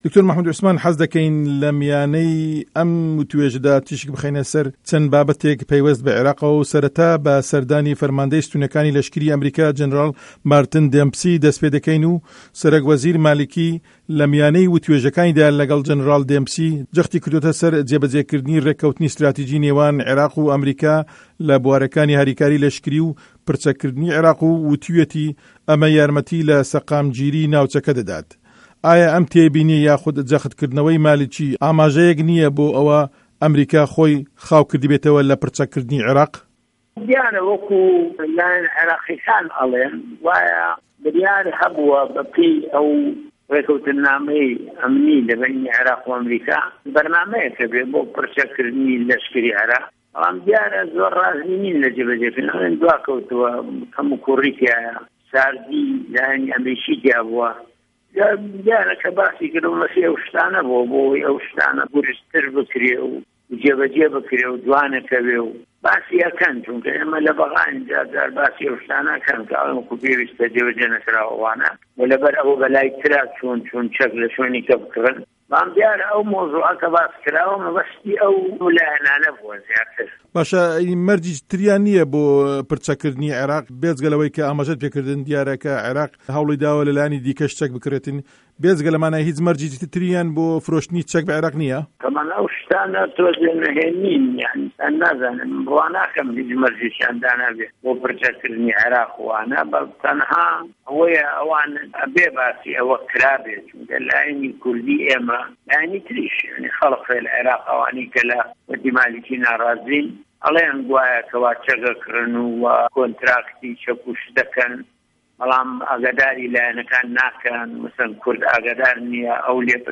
وتووێژ له‌گه‌ڵ مه‌حمود عوسمان